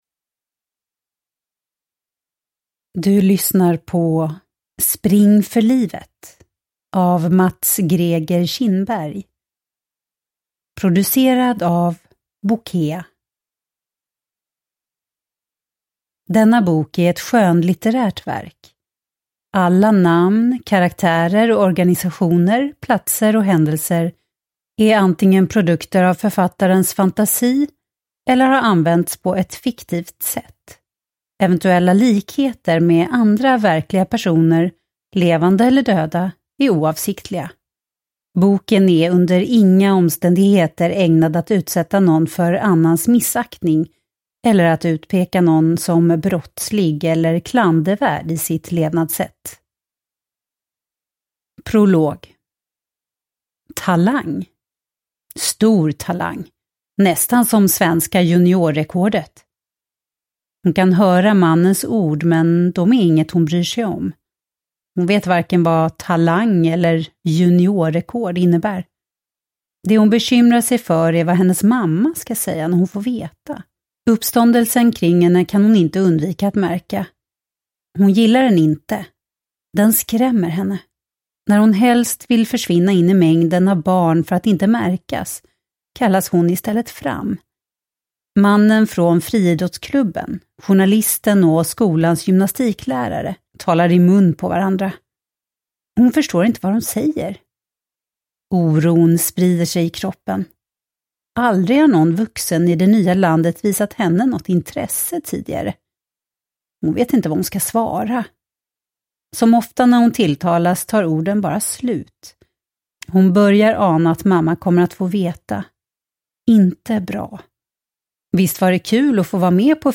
Spring för livet – Ljudbok